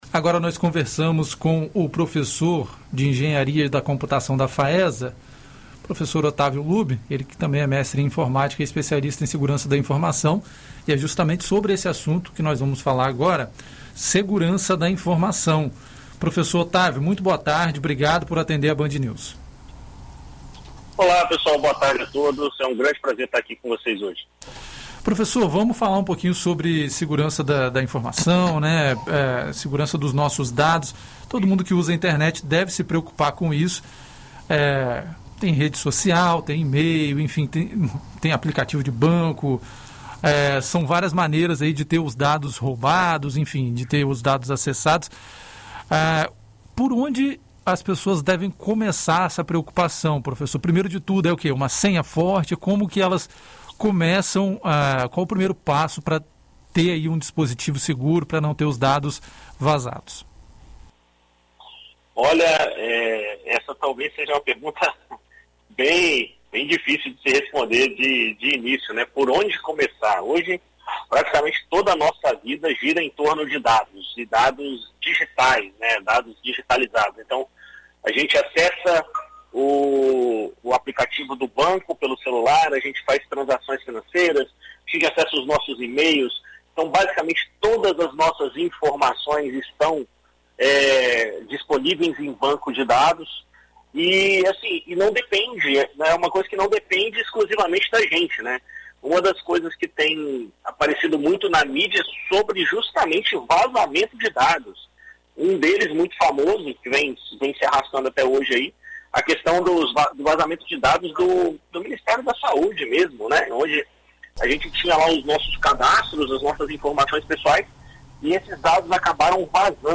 Em entrevista à BandNews FM Espírito Santo nesta segunda-feira (07)